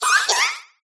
sobble_ambient.ogg